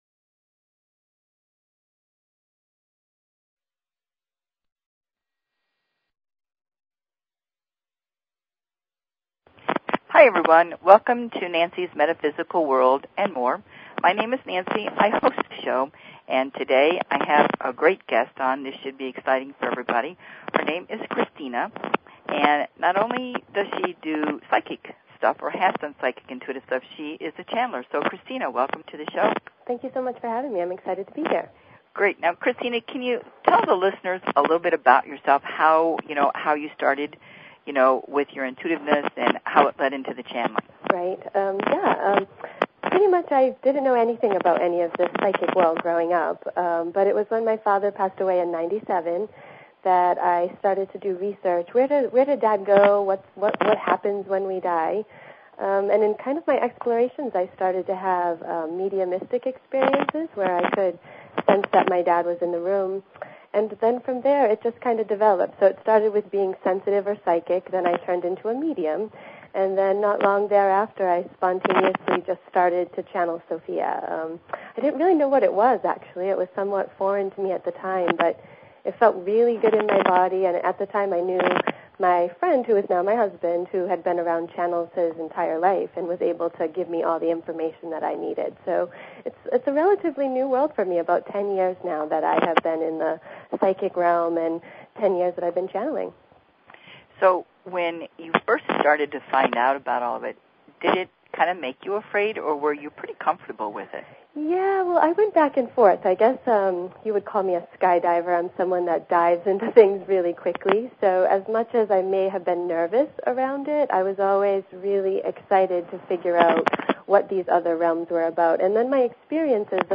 Talk Show Episode, Audio Podcast
She communicates within a relaxed, yet insightful conversational style, filled with surprise and humor.